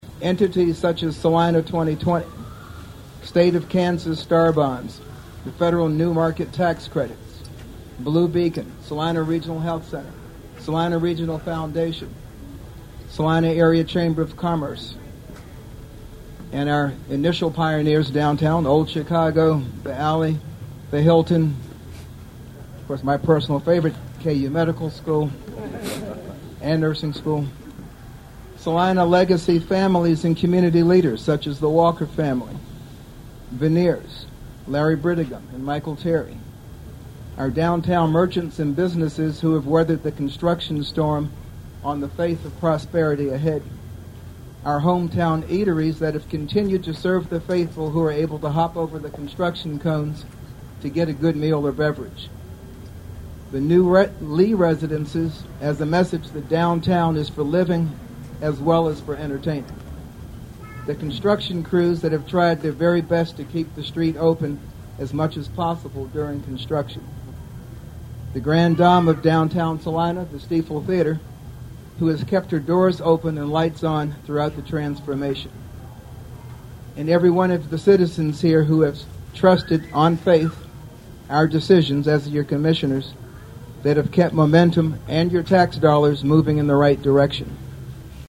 A large crowd gathered late in afternoon to celebrate the completion of what many believe is a “generational  project”, the downtown streetscape project.
Salina Mayor Dr. Trent Davis told the crowd a project of this magnitude could not have been accomplished without a lot of cooperation and partnerships.